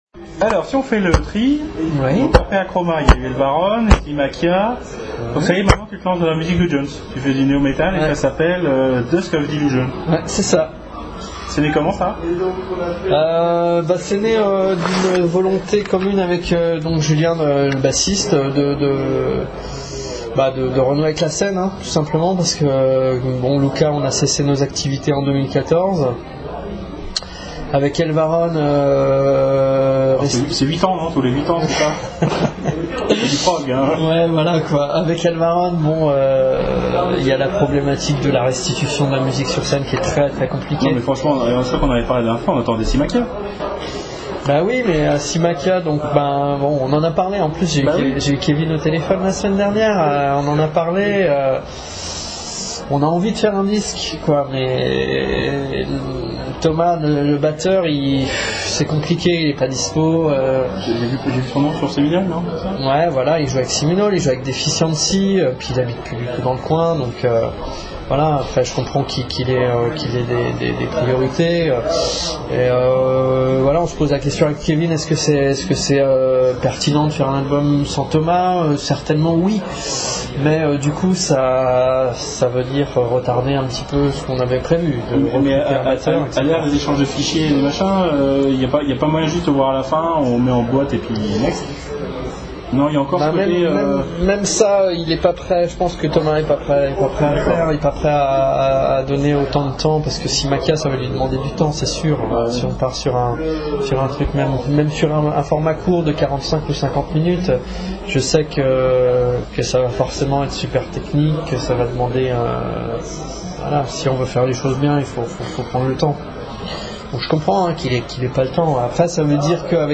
DUSK OF DELUSION (interview